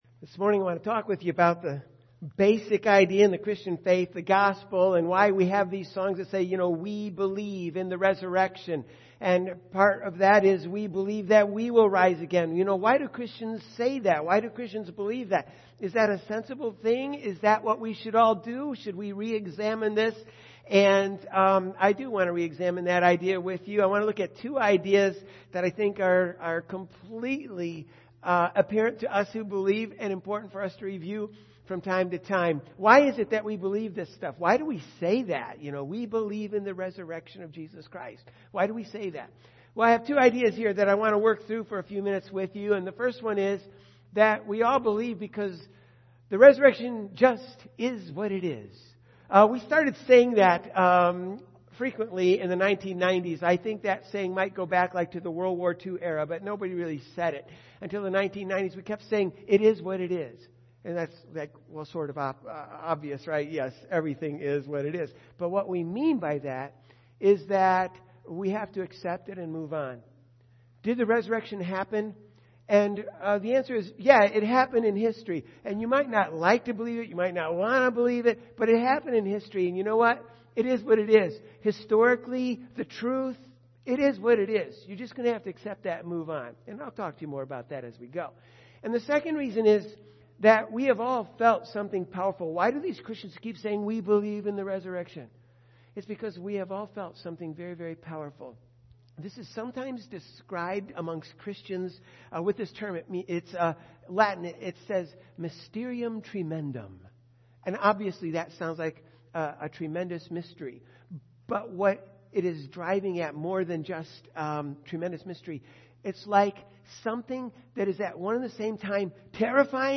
Resurrection Sunday Service